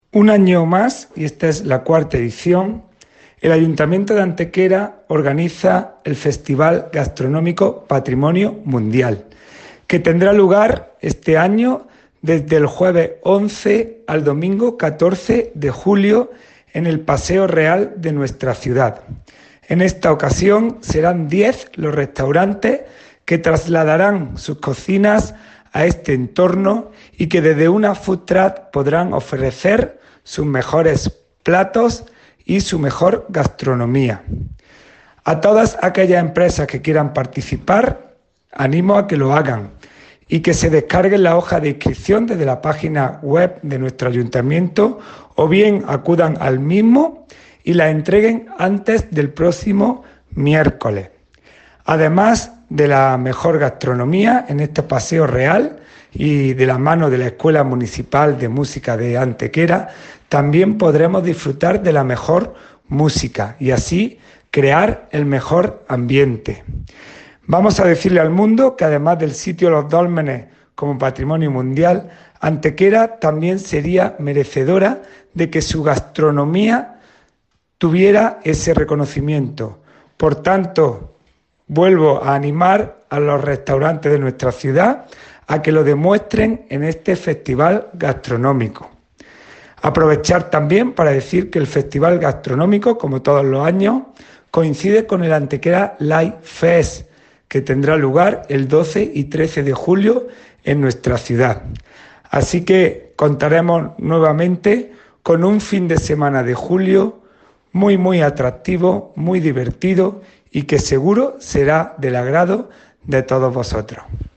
El teniente de alcalde delegado de Turismo, Hacienda, Juventud y Patrimonio Mundial, Juan Rosas, confirma que el Paseo Real volverá a acoger, del 11 al 14 de julio y de 21:00 a 02:00 horas, el IV Festival Gastronómico Patrimonio Mundial, iniciativa que volverá a desarrollarse de forma paralela en el contexto de la celebración del Antequera Light Fest (ALF).
Cortes de voz J. Rosas 781.92 kb Formato: mp3